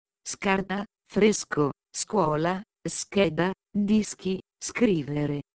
letters pronunciation examples English translation /sk/